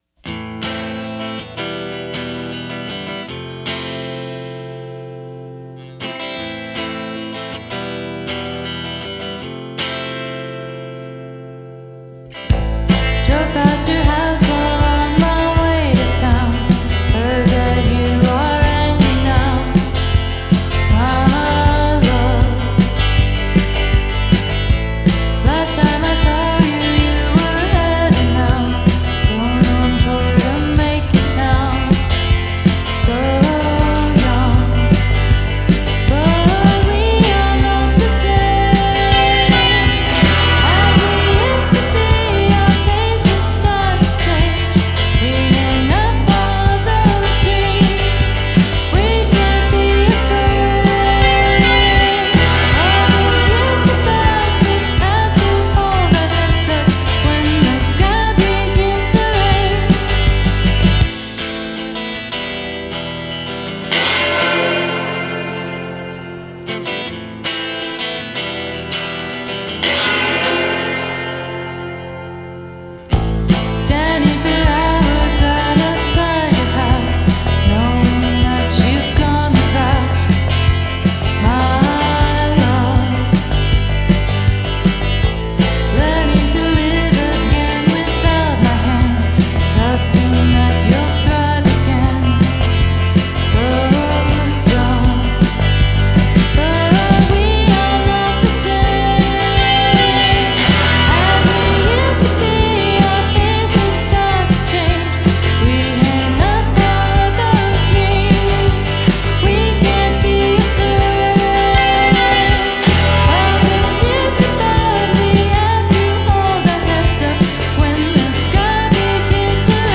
Genre: Indie Pop